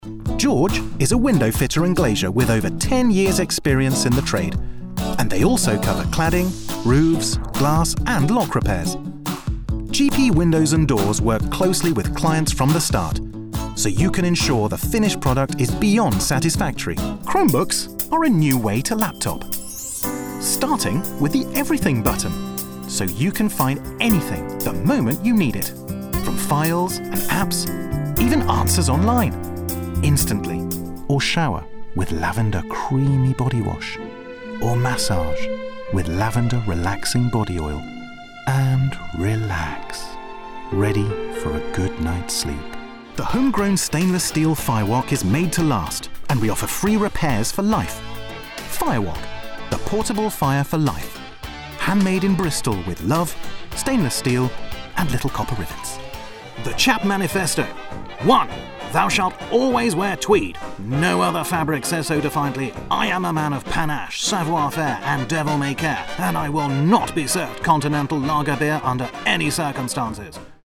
English (British)
Commercial Demo
Baritone
WarmFriendlyReliableAssuredEngaging